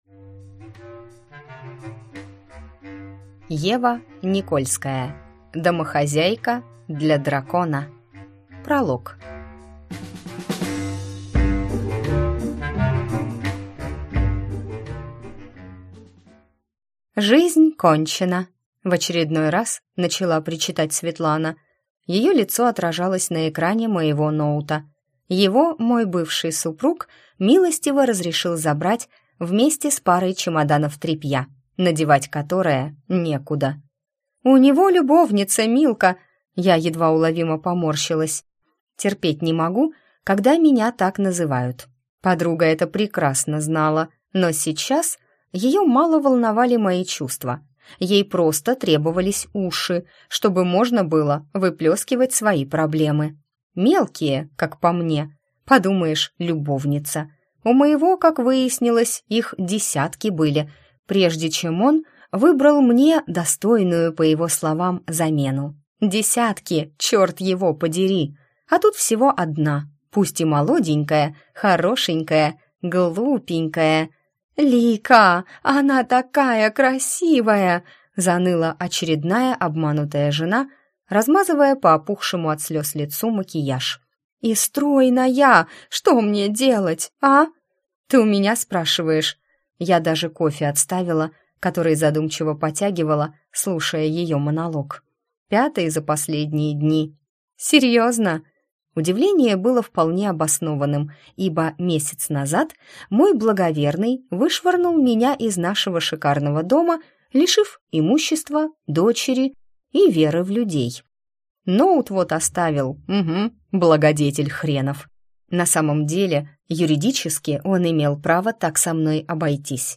Аудиокнига Домохозяйка для дракона | Библиотека аудиокниг